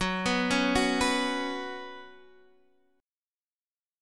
F#sus4 chord